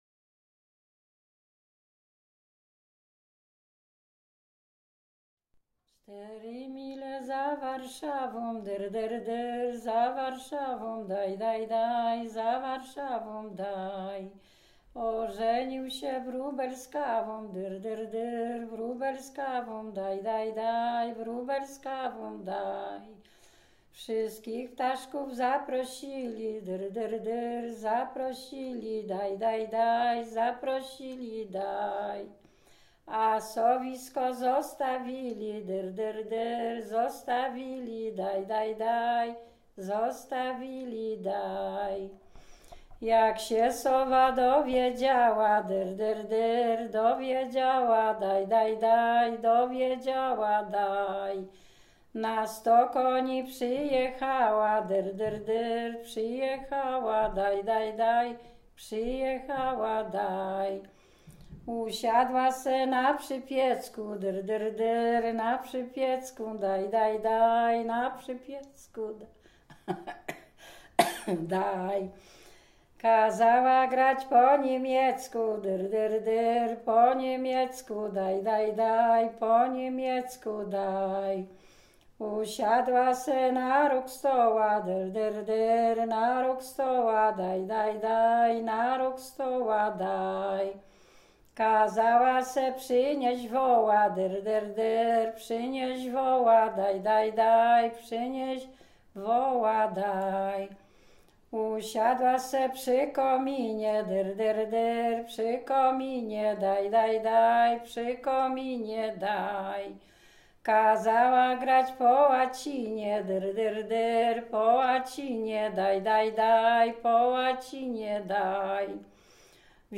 Dolny Śląsk, powiat bolesławiecki, gmina nowogrodziec, wieś Milików
Kolęda